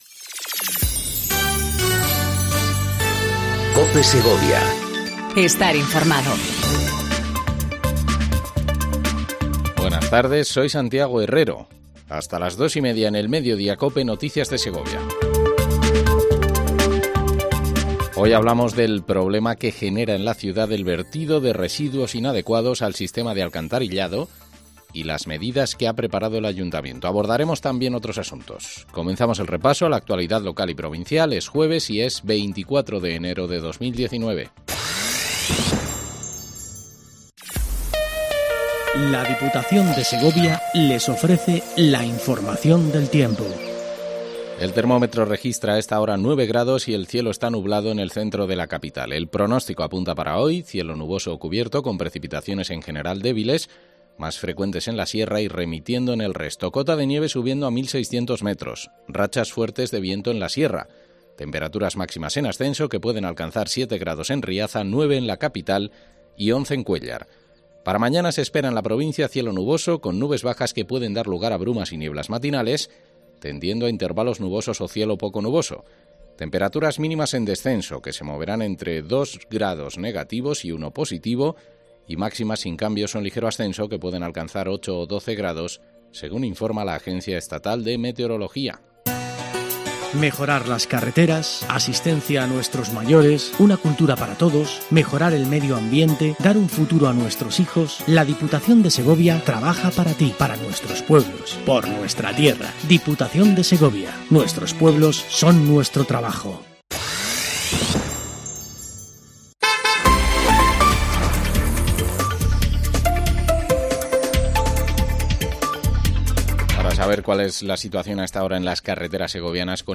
INFORMATIVO DEL MEDIODÍA EN COPE SEGOVIA 14:20 DEL 24/01/19